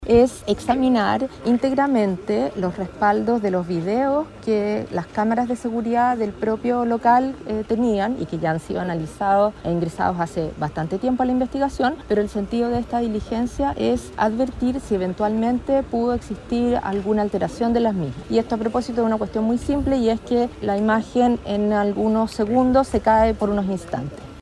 Al respecto, la fiscal regional de Valparaíso, Claudia Perivancich, detalló que se analizarán nuevamente los respaldos de la grabación, con el fin de resolver si es que hubo una alteración previa a la entrega de estos.